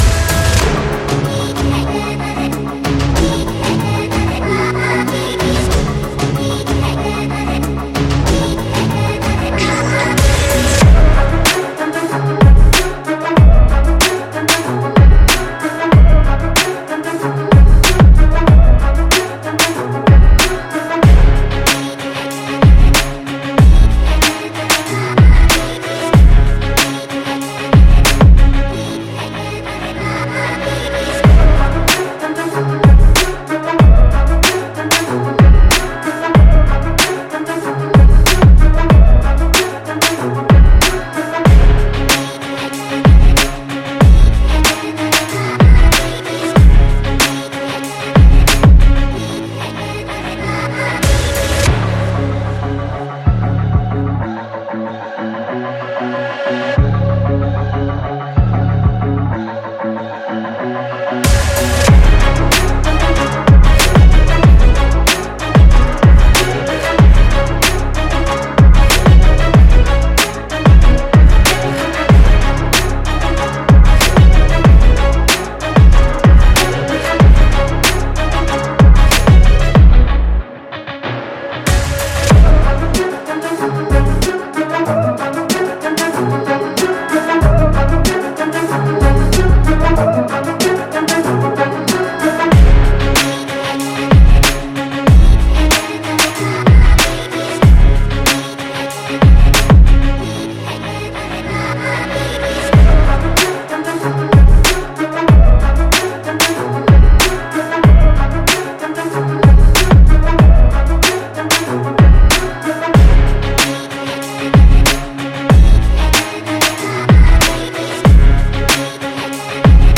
Hard_Uplifting_Rap_Beat__.mp3